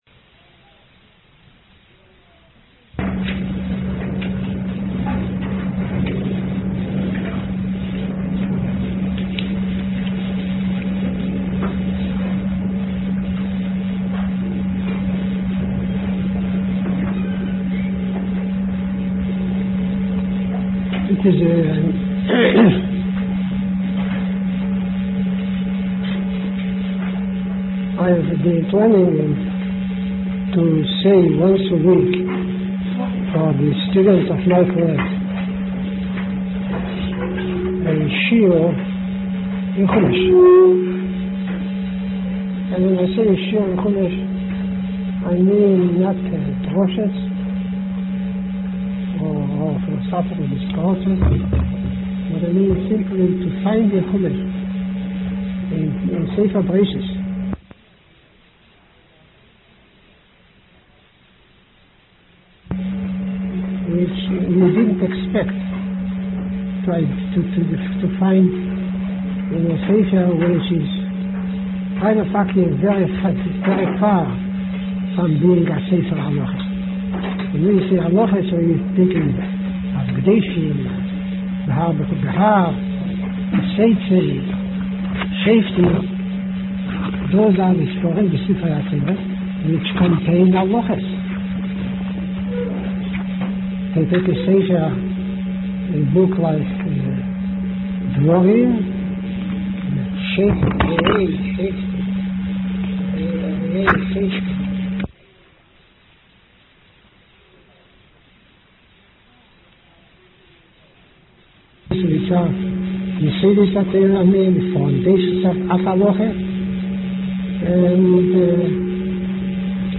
YU Chumash Shiur 1982